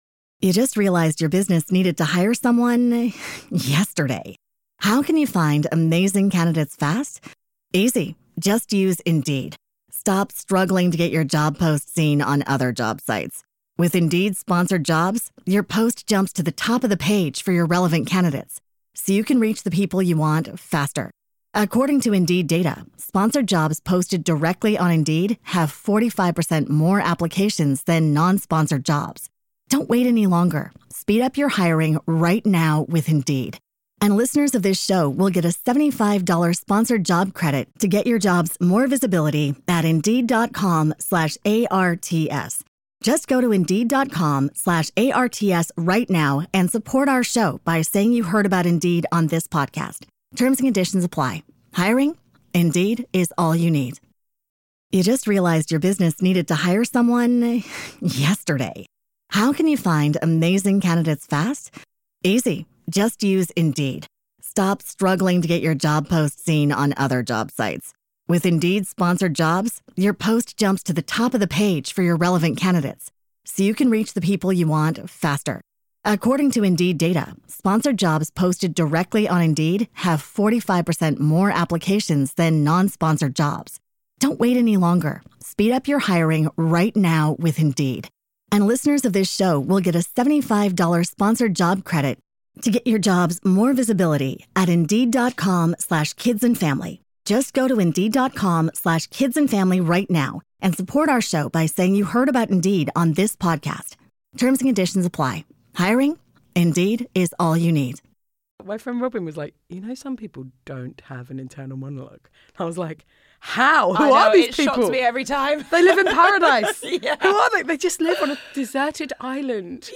Comedian Josie Long joins Vick to discuss internal monologues, her big move to Scotland and how ADHD is changing the way she sees the world and herself.